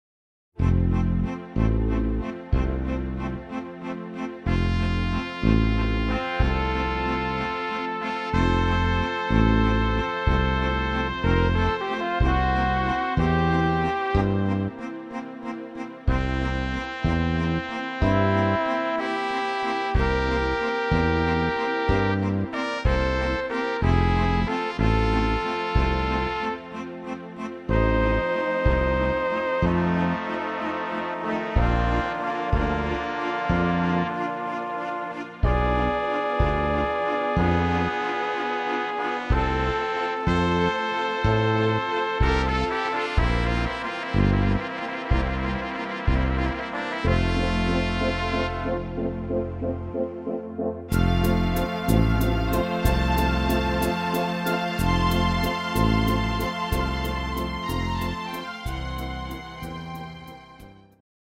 instrumental Big Band